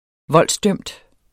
Udtale [ -ˌdœmd ]